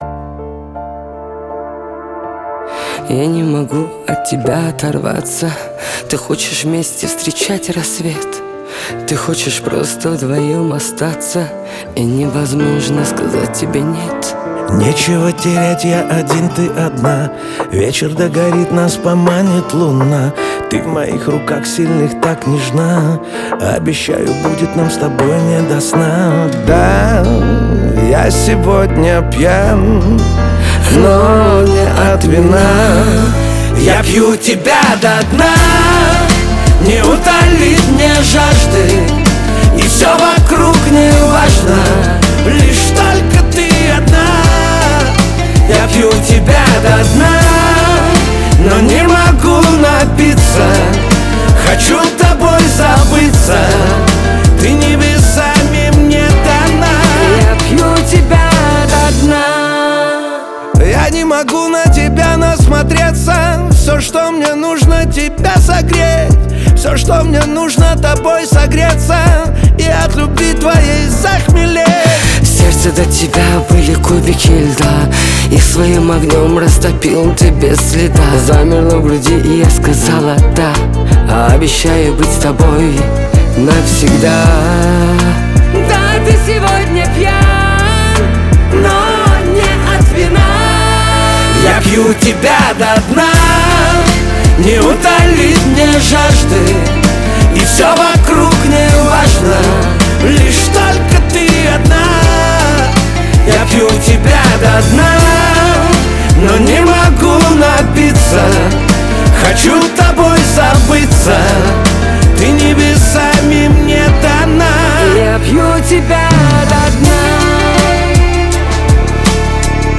Жанр Рэп.